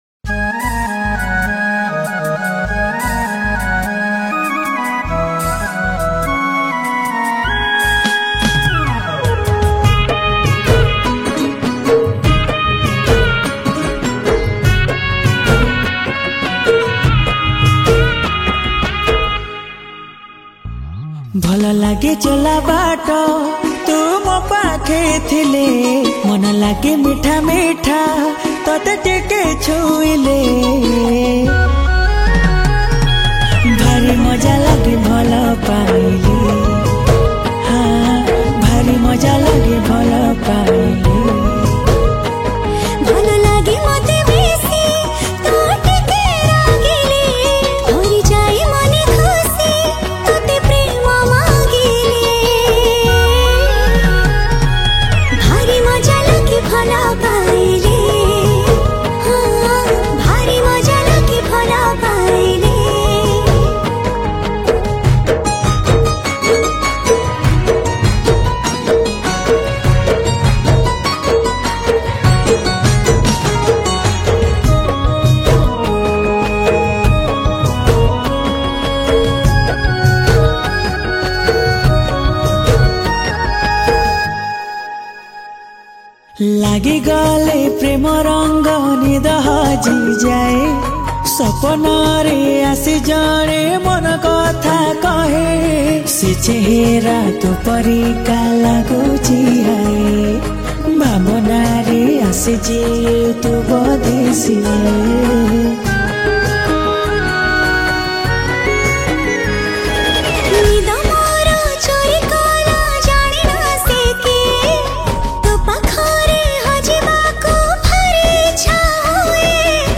Keyboard
Acoustic Rhythms